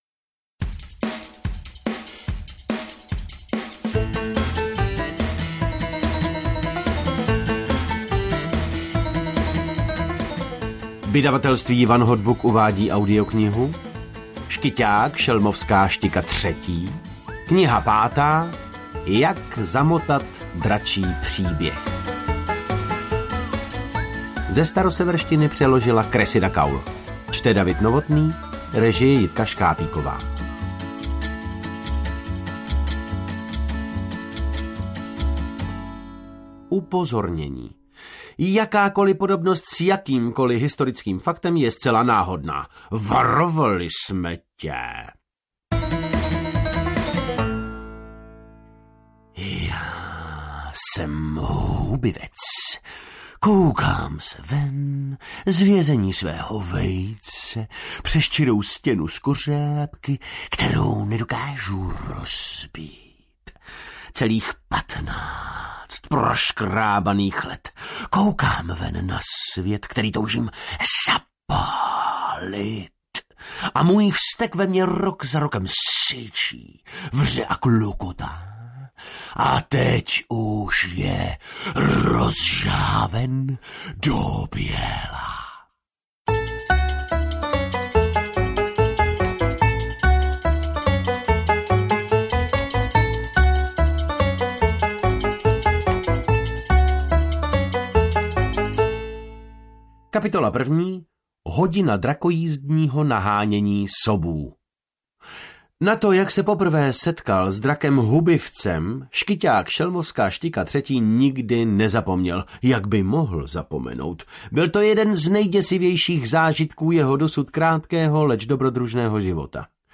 Jak zamotat dračí příběh audiokniha
Ukázka z knihy